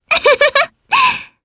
Frey-Vox_Happy2_jp.wav